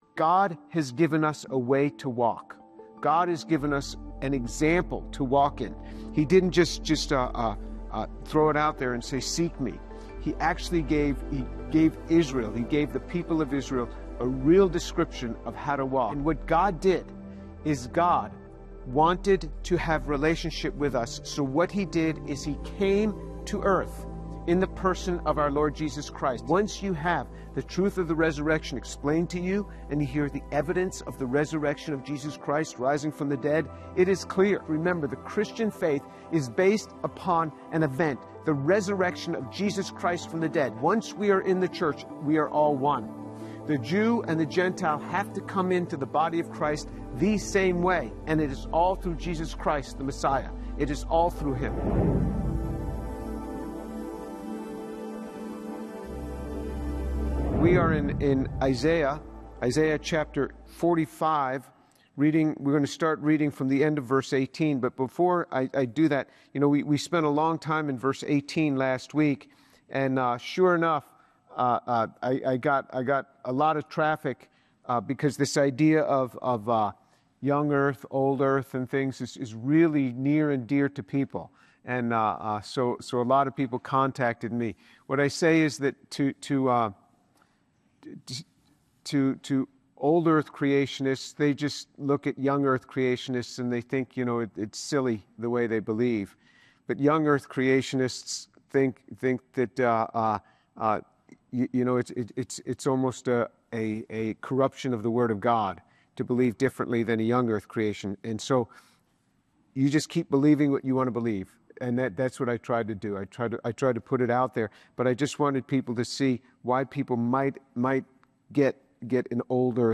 Dr. James Tour teaches on Isaiah 45 explaining that God clearly revealed Himself to Israel and ultimately provided the perfect example for how to live through Jesus Christ. Salvation is offered to all people—both Jews and Gentiles—through faith in the resurrected Christ, while idols and human works cannot save.